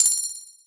coingain.wav